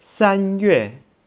(Click on any Chinese character to hear it pronounced.
sanyue.wav